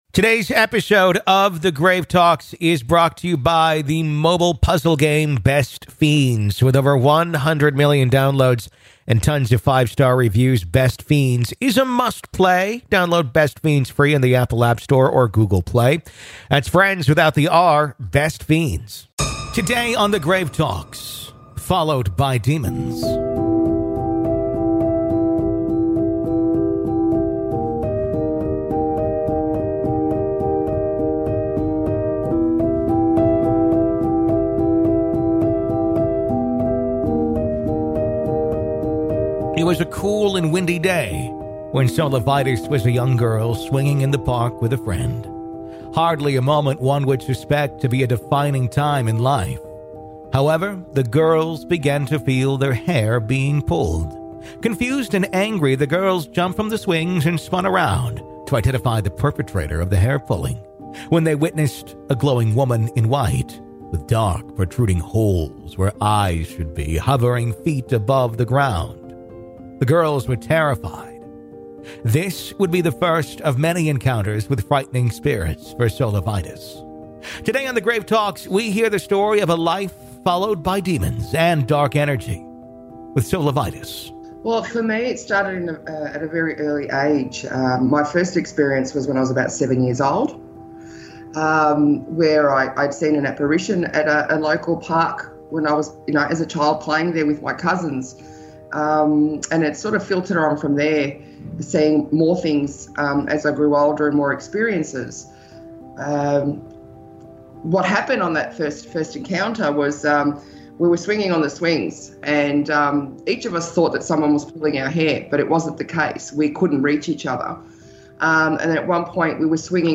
LISTEN HERE In part two of our interview